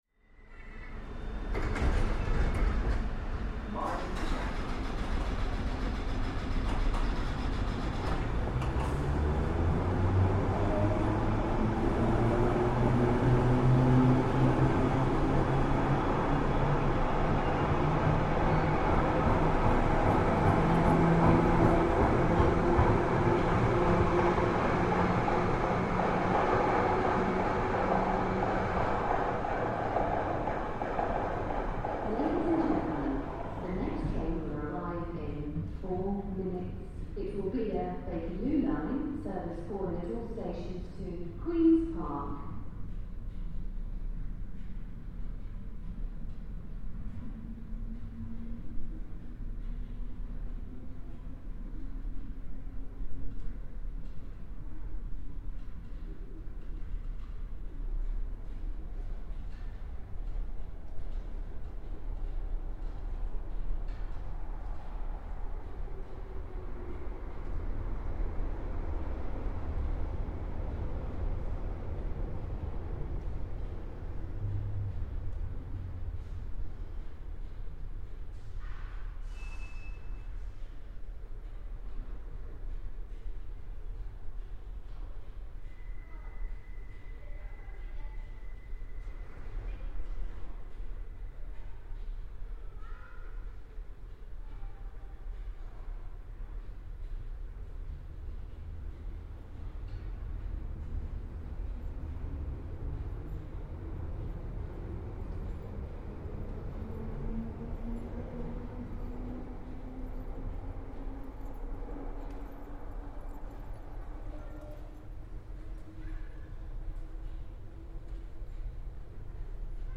Field recording from the London Underground by Cities and Memory.